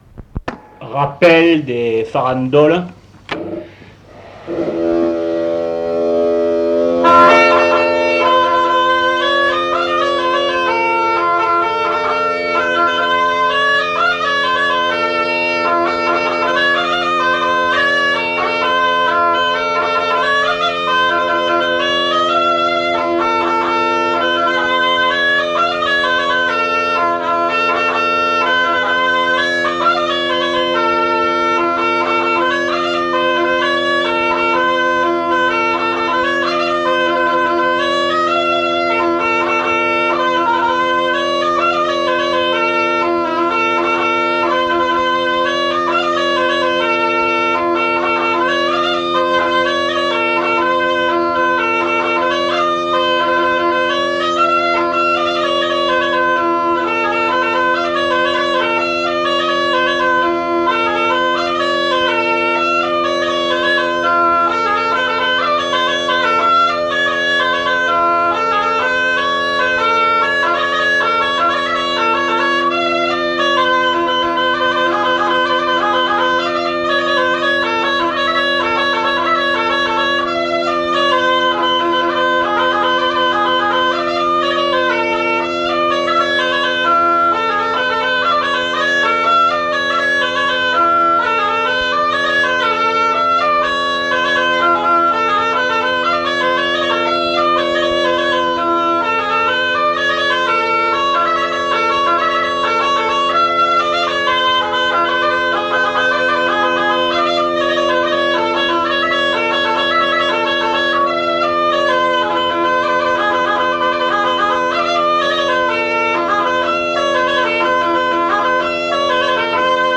Airs de chansons et de danses interprétés à la bodega
enquêtes sonores
variation de tonalités